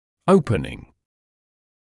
[‘əupənɪŋ][‘оупэнин]открывание, раскрытие; инговая форма от to open